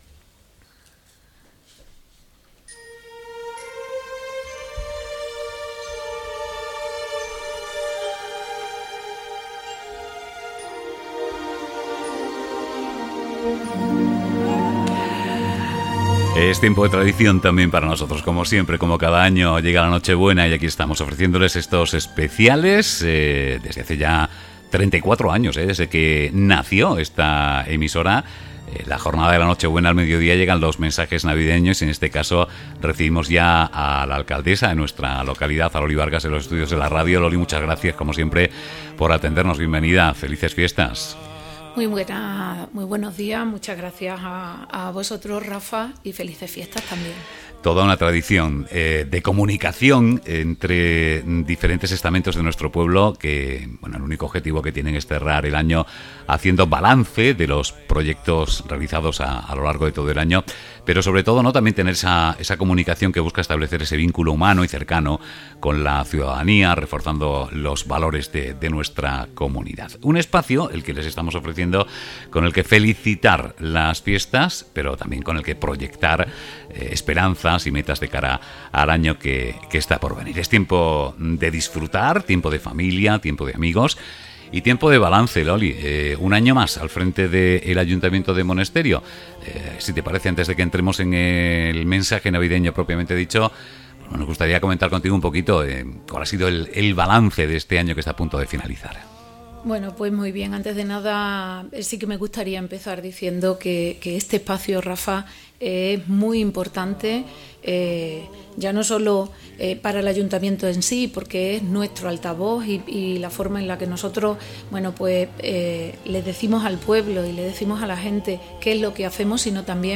En su discurso navideño la alcaldesa de Monesterio anuncia los principales proyectos para 2026